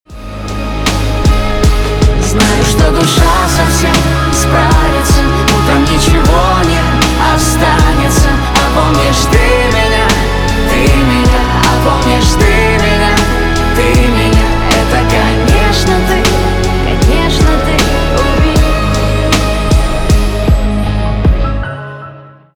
поп
грустные
чувственные , пианино